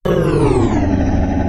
ghoul_dies.ogg